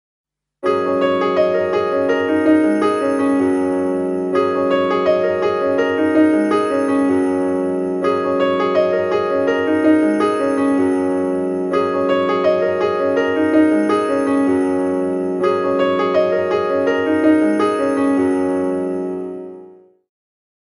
ピアノの速い音節と鈴 ver.B.mp3